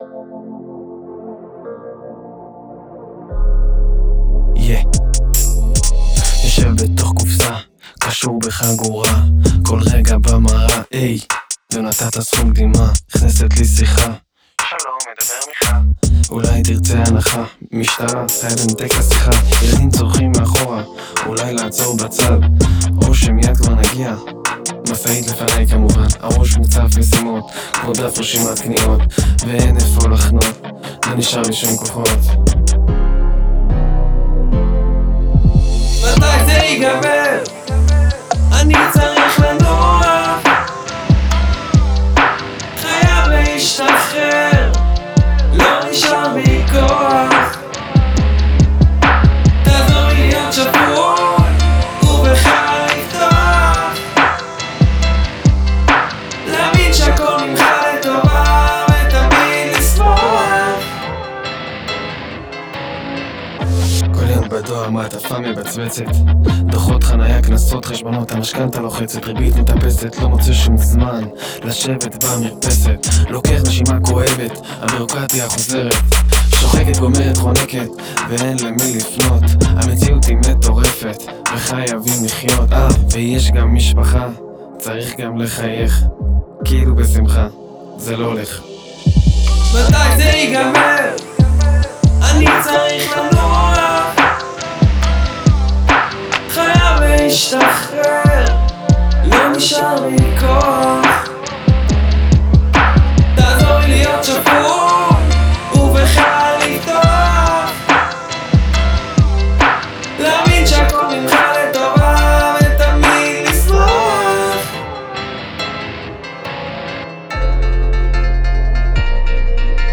יש לך קול יפה שגם מתאים לז’אנר העיבוד גם מוצלח אם ביט לפרצוף